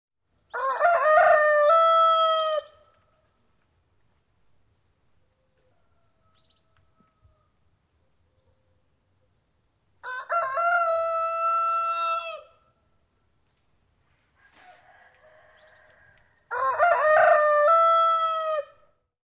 Звуки петуха
Крики петухов, деревенская атмосфера (2)